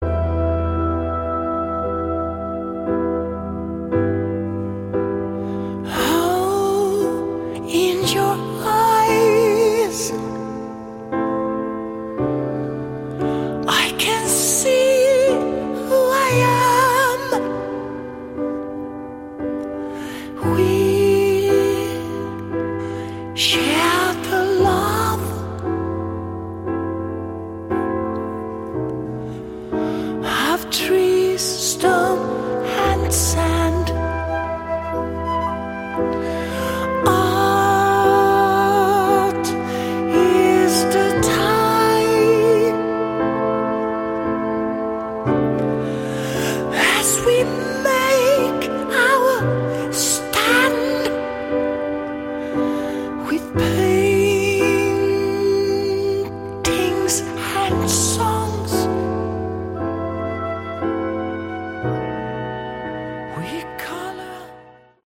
Category: Prog Rock
vocals
keyboards
guitar
bass
drums